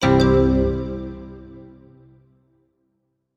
Interface Positive Button.wav